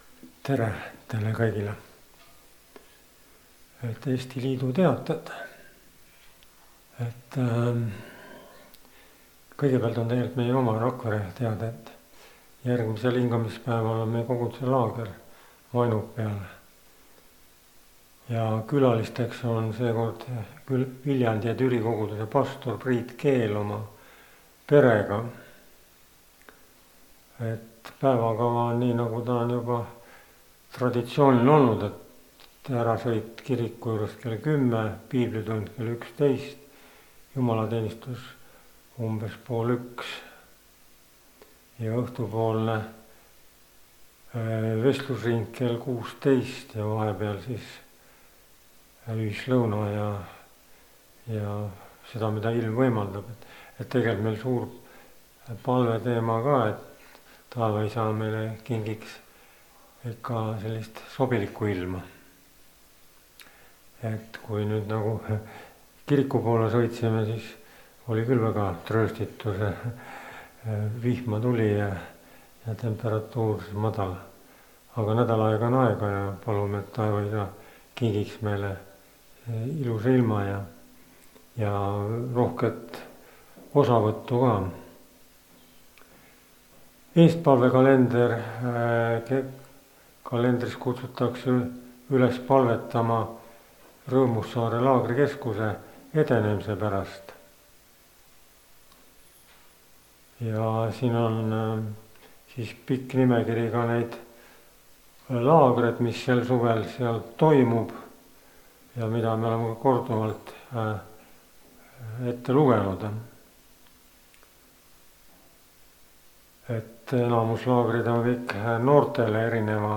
kategooria Audio / Koosolekute helisalvestused